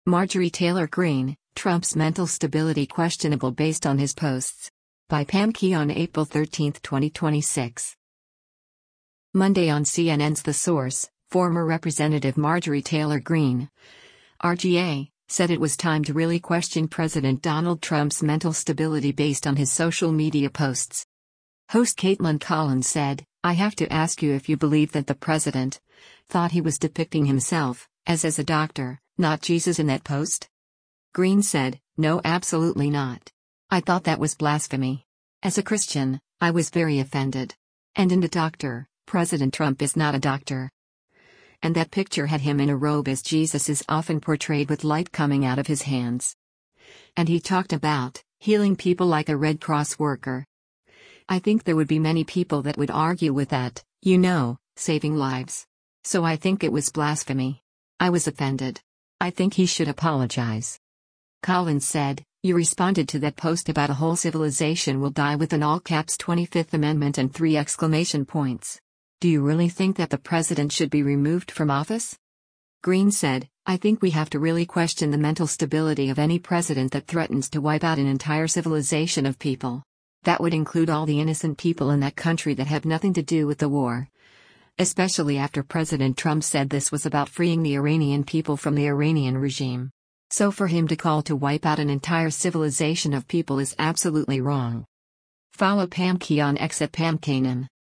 Host Kaitlan Collins said, “I have to ask you if you believe that the president, thought he was depicting himself, as as a doctor, not Jesus in that post?”